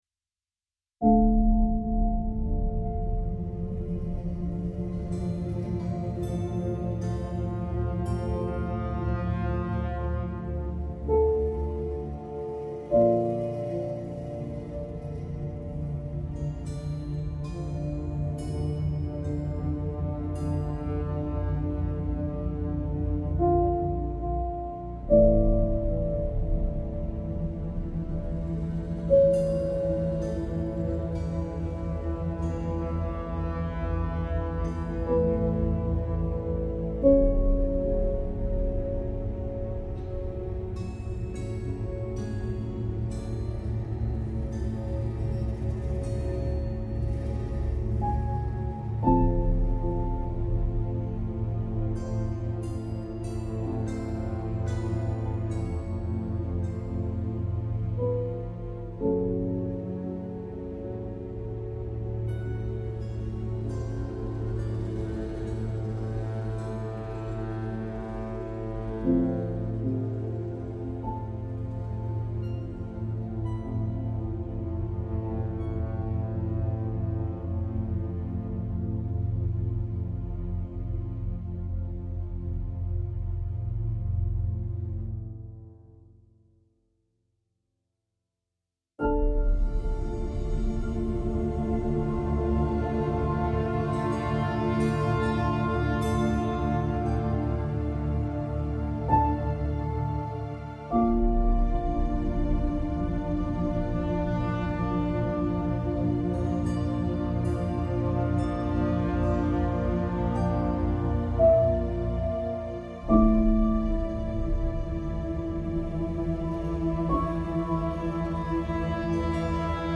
metal strings